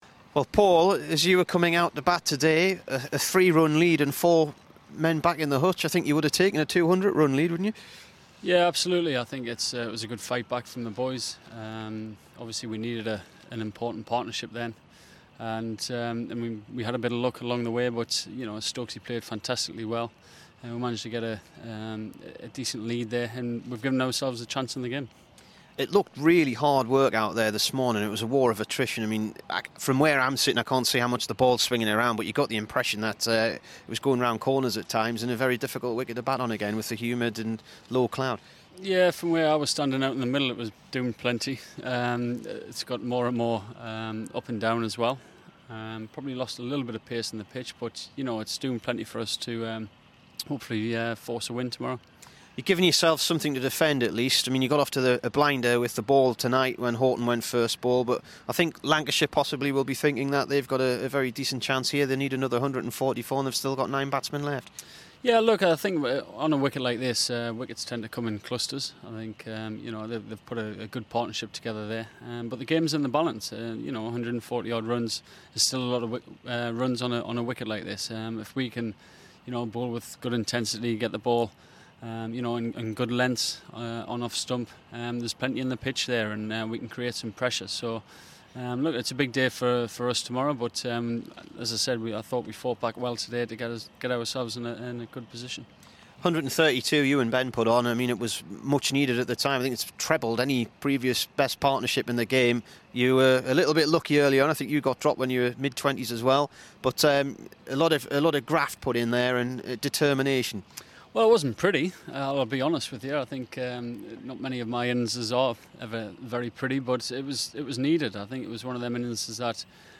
PAUL COLLINGWOOD DAY 3 LANCS INT